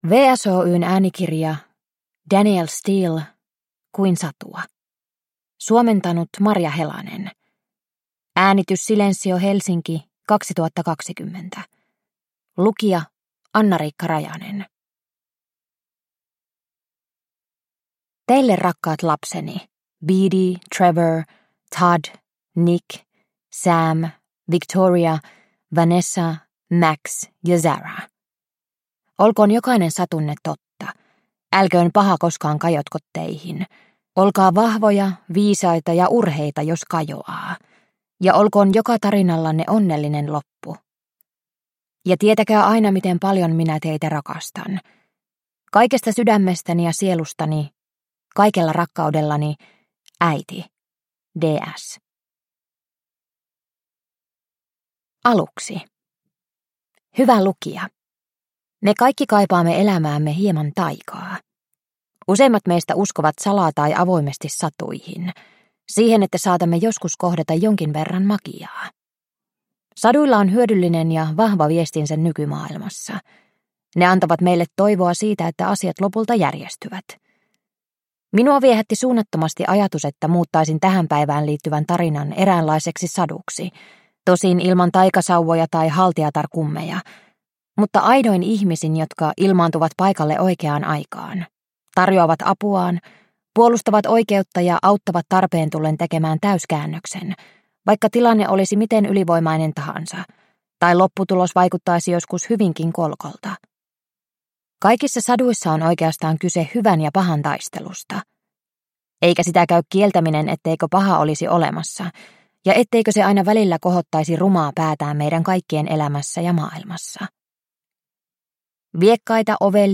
Kuin satua – Ljudbok – Laddas ner